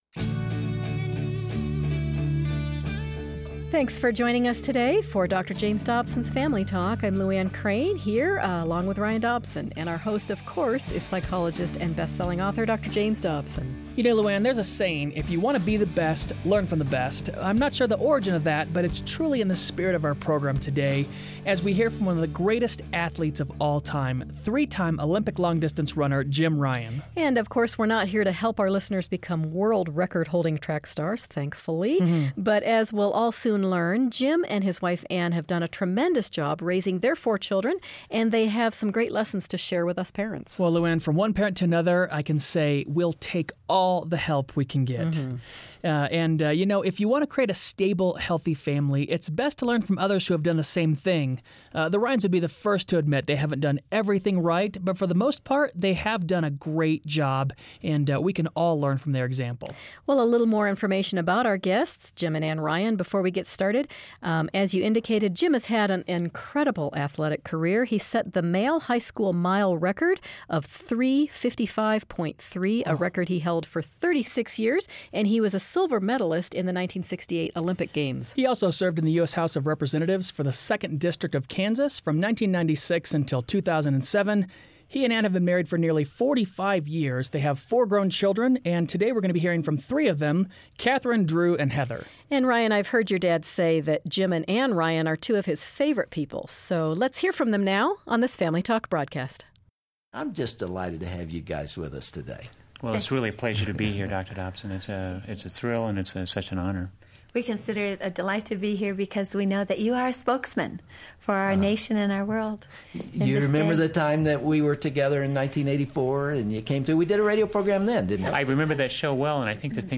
Dr. Dobson sits down with Olympic runner, Jim Ryun and his family. While they will be the first to admit they are FAR from perfect, they have a lot of wisdom to share with other parents about schooling, discipline and dating. Don't miss this practical conversation for families.